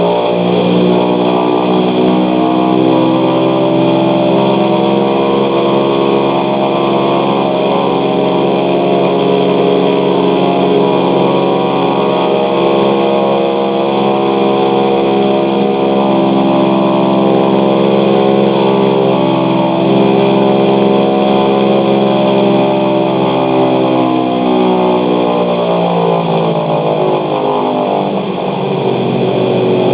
DC-3 Sound Files
Pre-flight number 1 (right) engine check from idle to full throttle to idle (recorded from inside the cockpit).